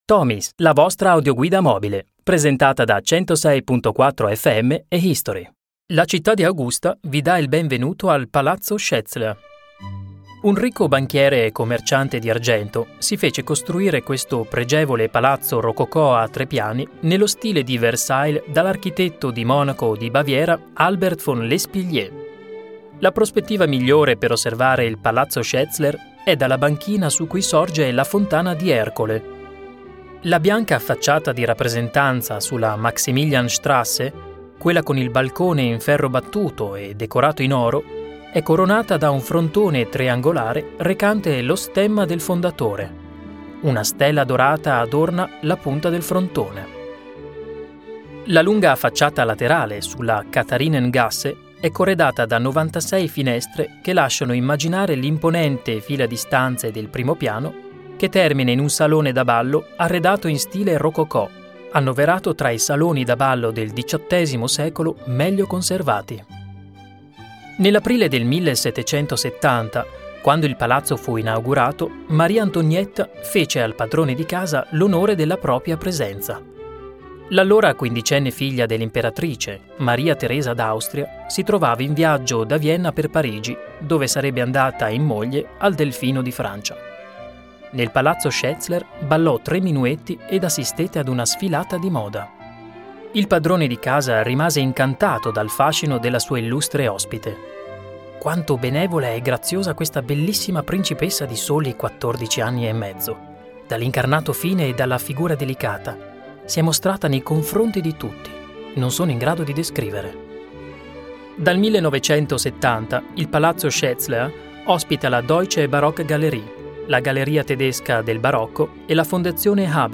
guida MP3 tomis